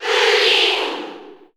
Crowd cheers (SSBU) You cannot overwrite this file.
Jigglypuff_Cheer_Japanese_SSB4_SSBU.ogg